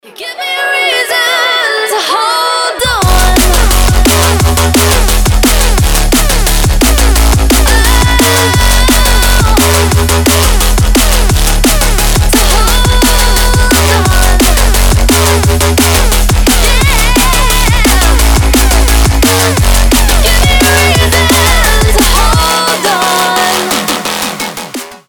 • Качество: 320, Stereo
громкие
жесткие
мощные
драм энд бейс